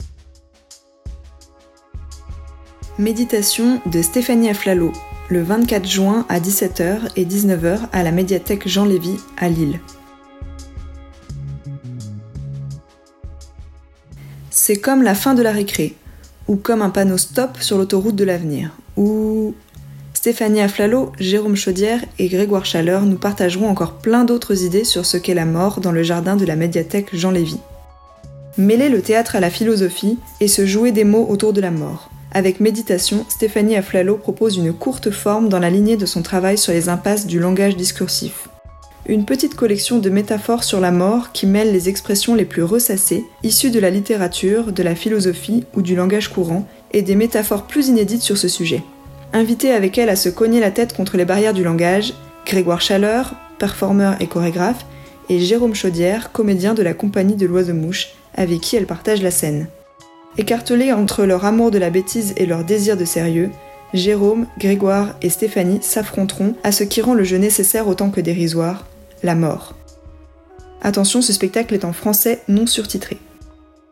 Version audio de la description du spectacle :